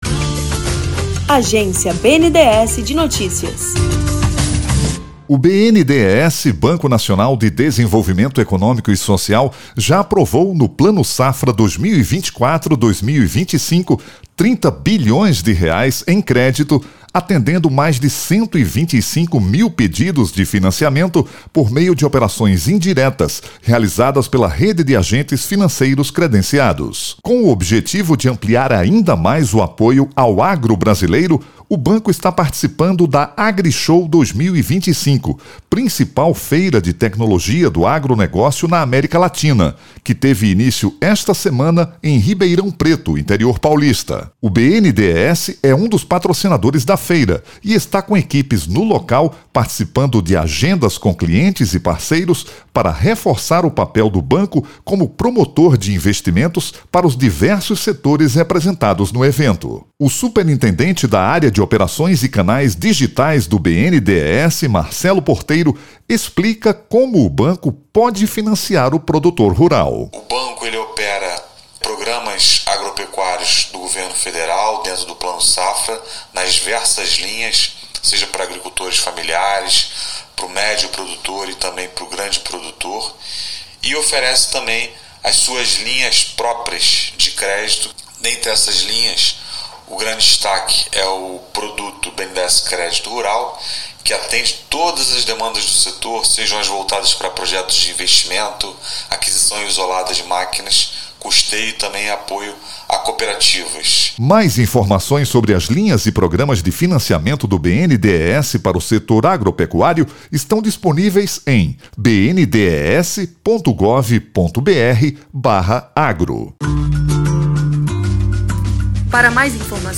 Áudio Release BNDES